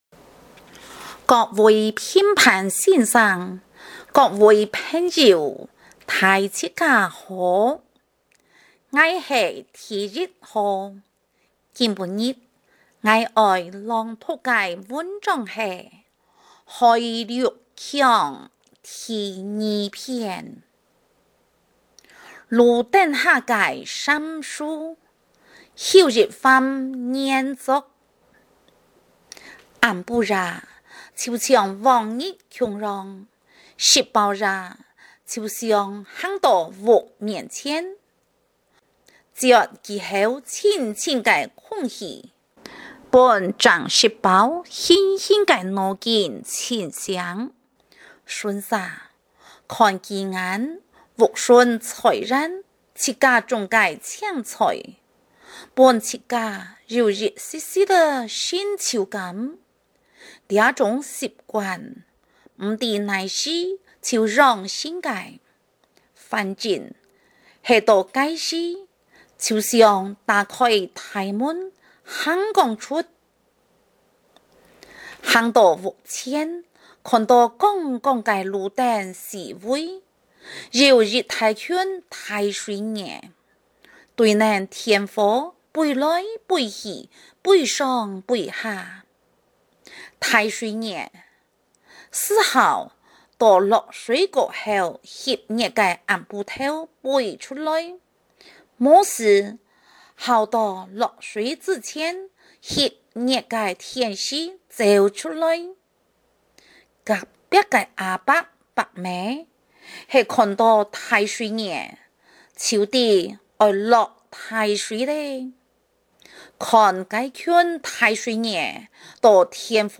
107學年度校內多語文競賽-客家語文章及音檔(海陸腔)